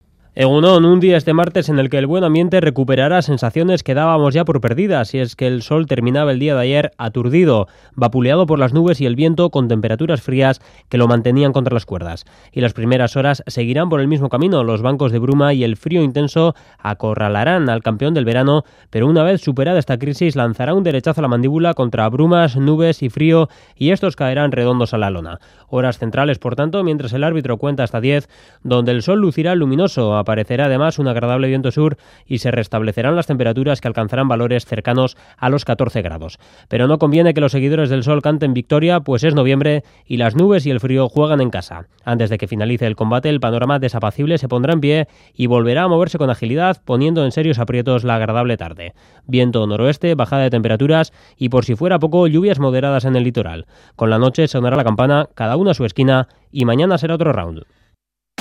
Previsión del tiempo